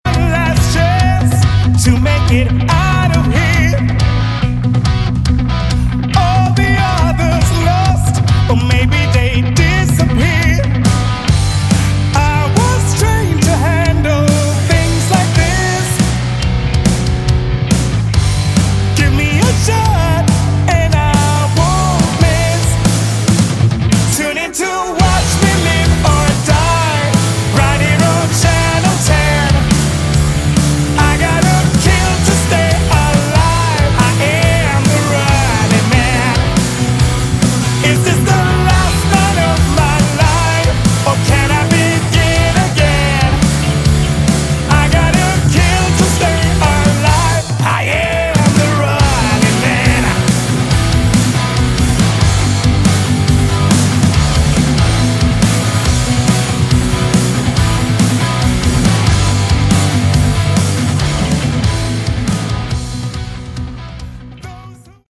Category: Hard Rock
lead vocals
bass
guitar
drums